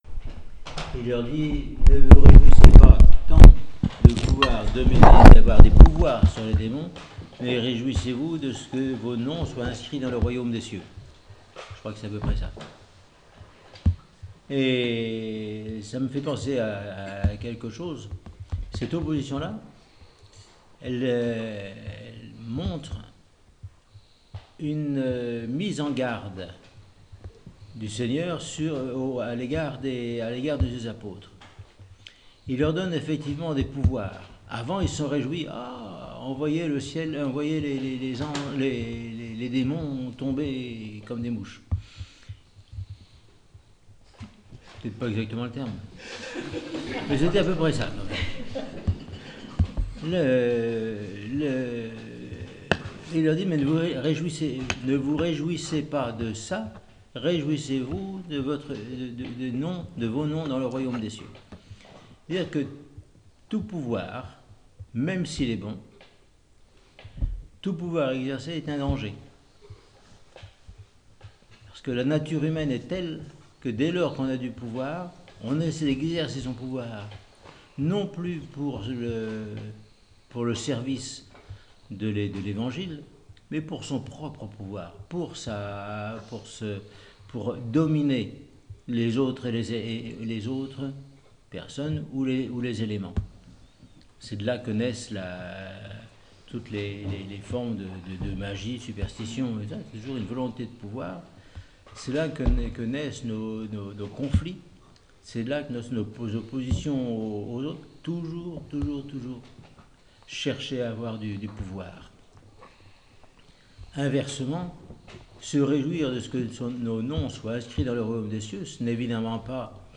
homélie
au monastère de la Transfiguration après la liturgie dominicale devant l’assemblée des moniales et des fidèles